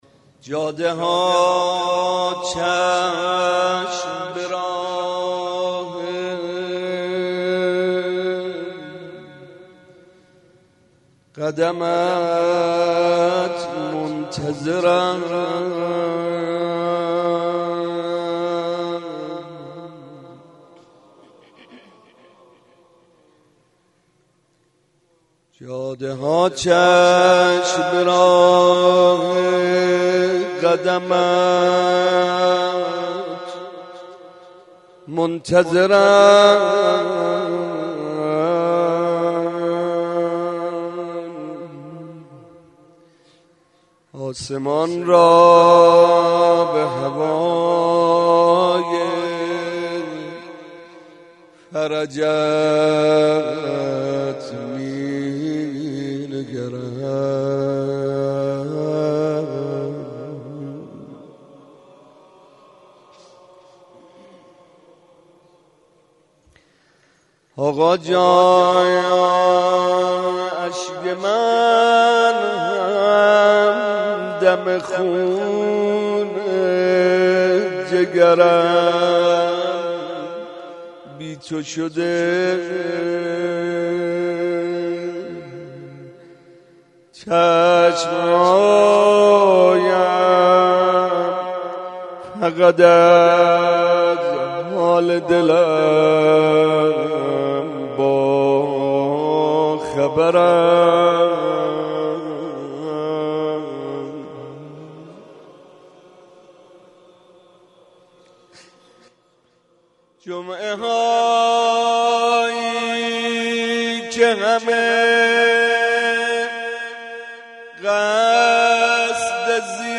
01.monajat.mp3